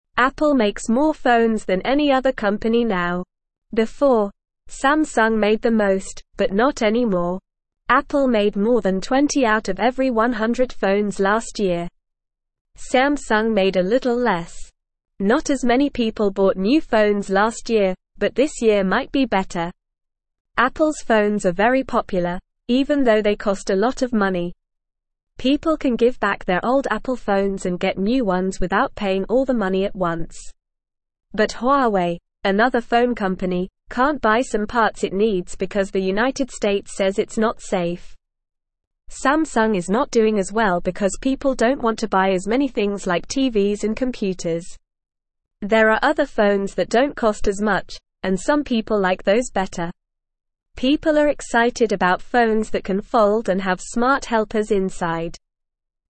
Normal
English-Newsroom-Beginner-NORMAL-Reading-Apple-Makes-the-Most-Phones-Samsung-Not-Doing-Well.mp3